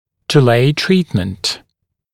[dɪ’leɪ ‘triːtmənt][ди’лэй ‘три:тмэнт]отложить лечение на более поздний срок